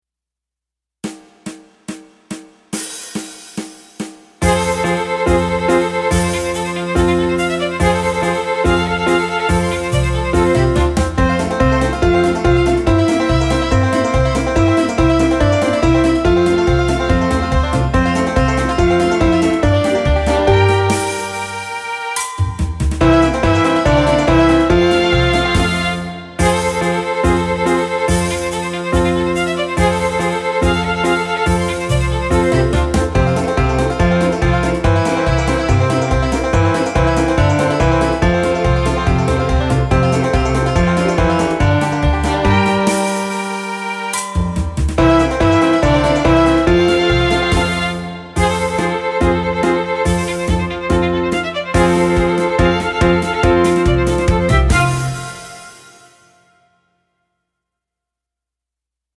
Performance-tempo samples:
Newly-orchestrated background accompaniments